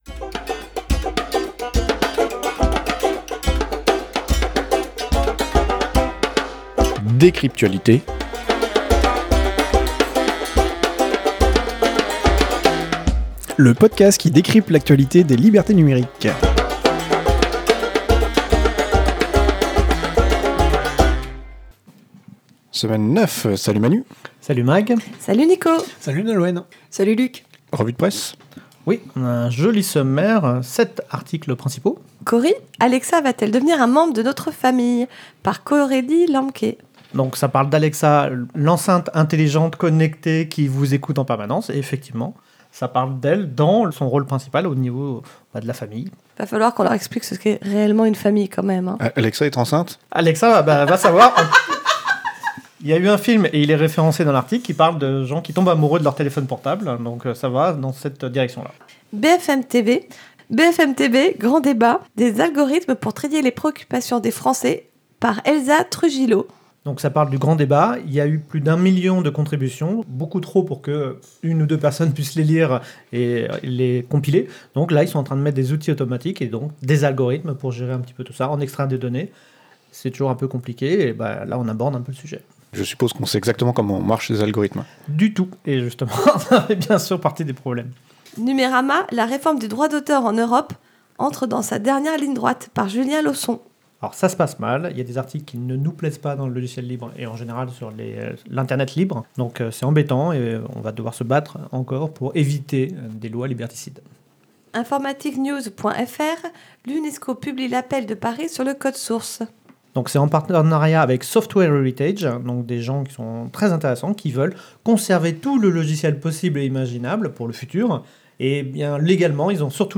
Revue de presse pour la semaine 9 de l’année 2019
Lieu : April - Studio d’enregistrement